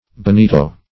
Bonito \Bo*ni"to\ (b[-o]*n[=e]"t[-o]), n.; pl. Bonitoes